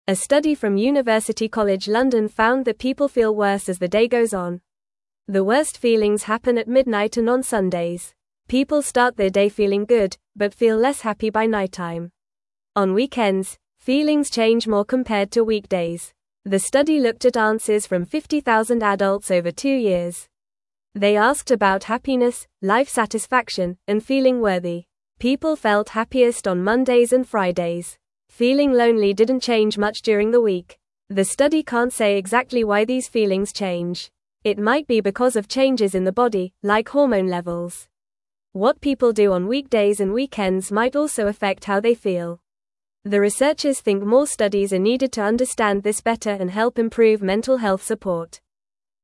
Fast
English-Newsroom-Lower-Intermediate-FAST-Reading-Why-People-Feel-Happier-at-the-Start-of-Days.mp3